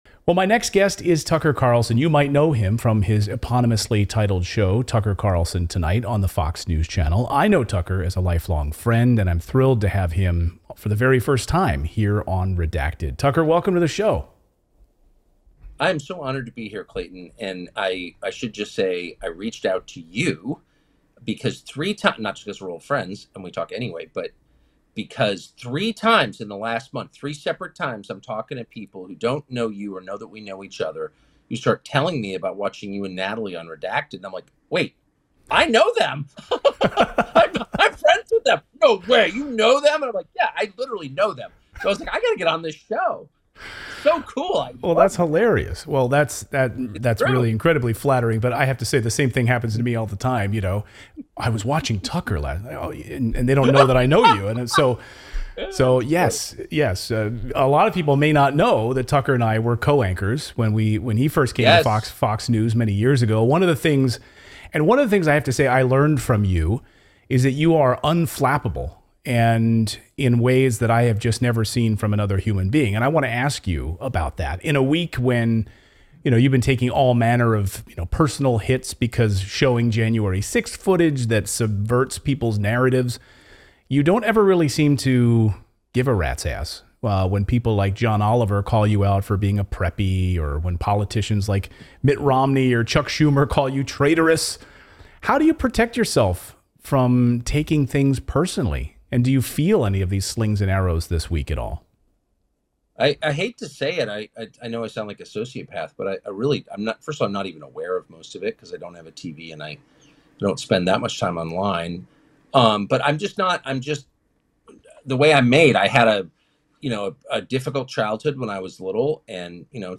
Tucker Carlson sits down with Redacted Host Clayton Morris for a wide ranging interview about the January 6th video controversy, the state of journalism, personal attacks and much more.